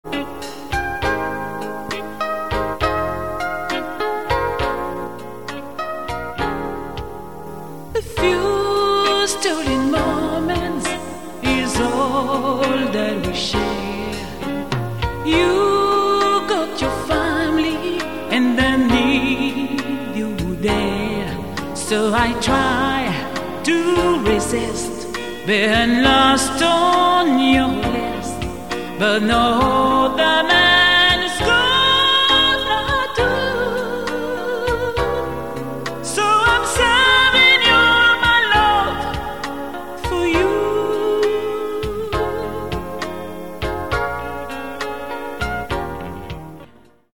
EXTRAIT SLOWS